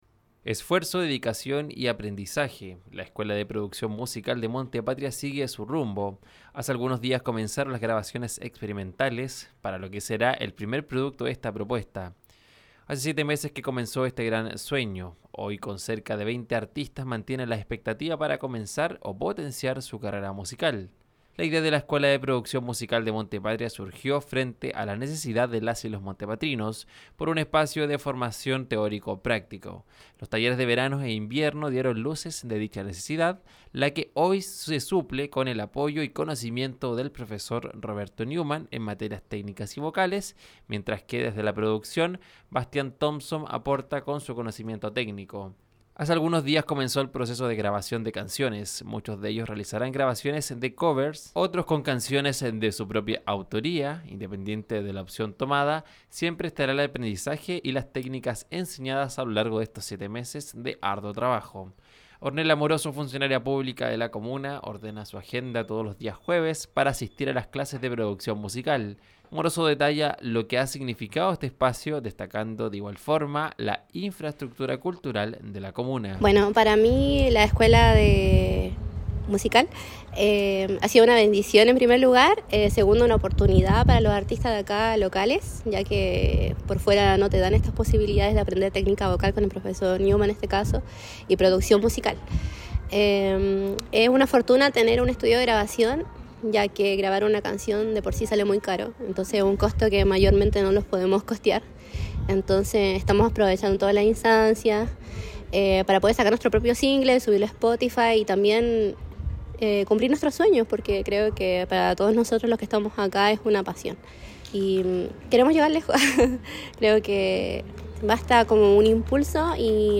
DESPACHO-ESCUELA-PRODUCCION-MUSICAL.mp3